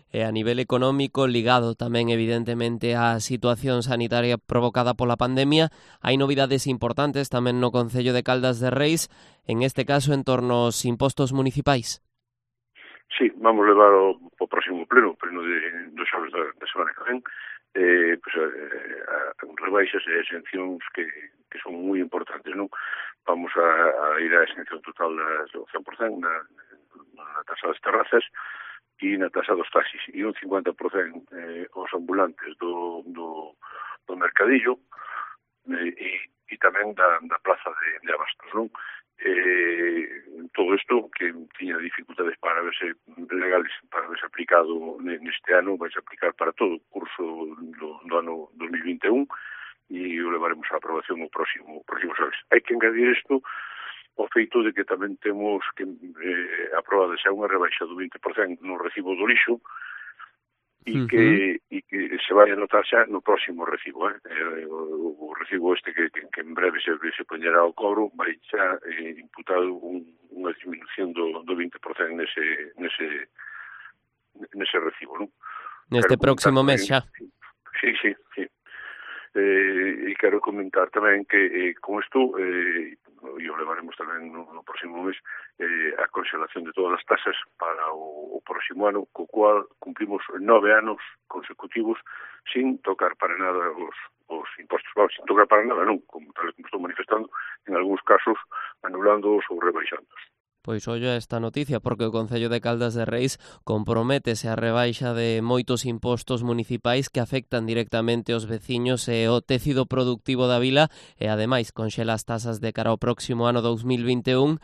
Declaraciones a Cope del alcalde de Caldas, Juan Manuel Rey, sobre las medidas fiscales